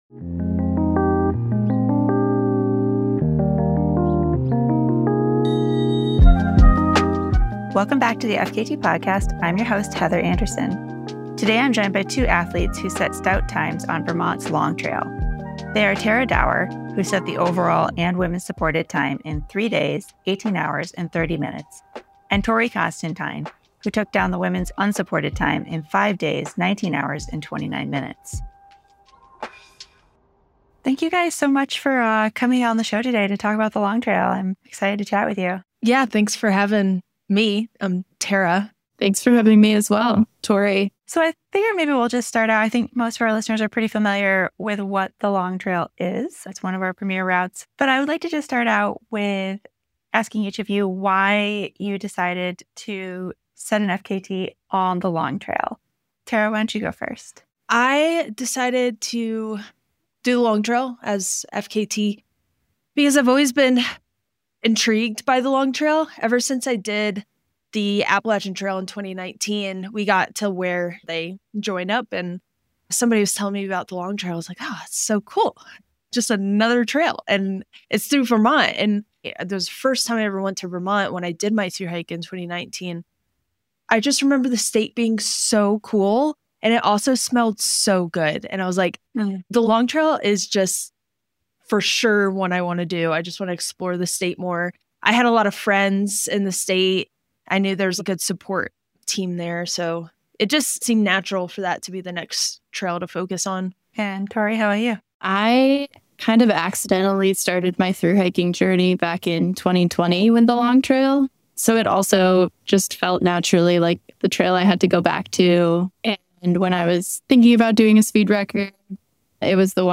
Log in or register to post comments Category Person-Person The 272 mile Long Trail is one of our premier routes for good reason.